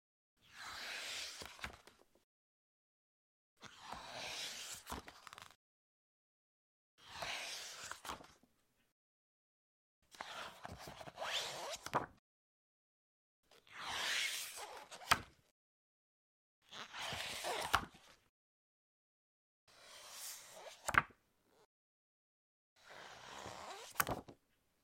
书本拿起放下 翻动
描述：书本拿起放下 翻动 au录制
标签： 书本 翻书 放下书
声道立体声